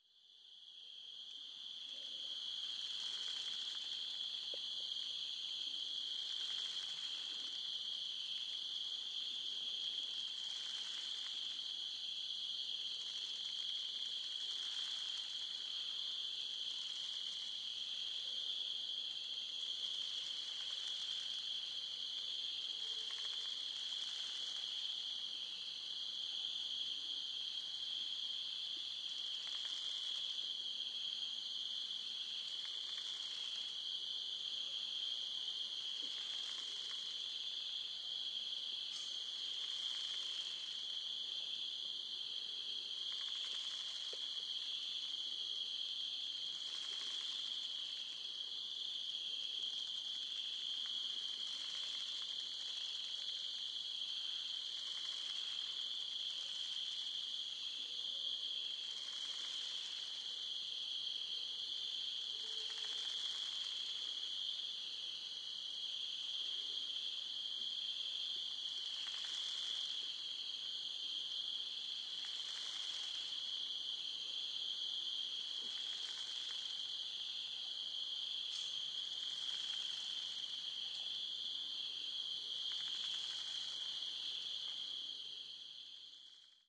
Звуки кустов
Звук крадущегося по кустам человека ночью